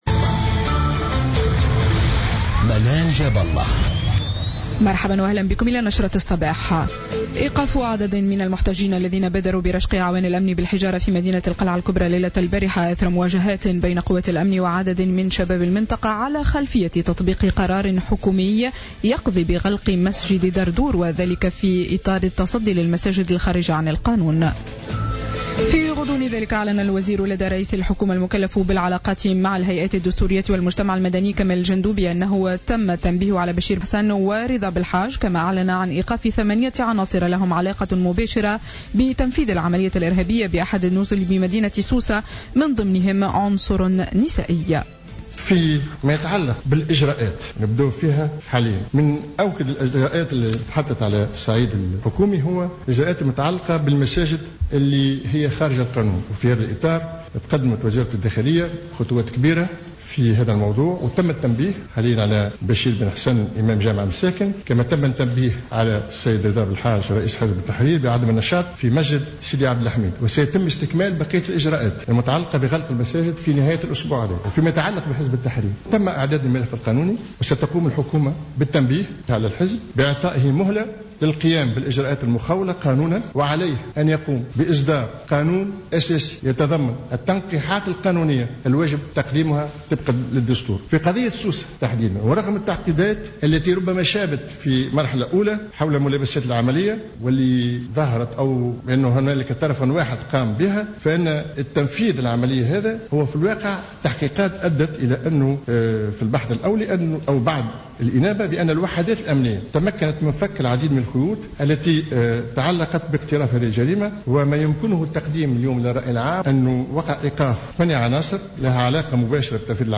نشرة أخبار السابعة صباحا ليوم الجمعة 03 جويلية 2015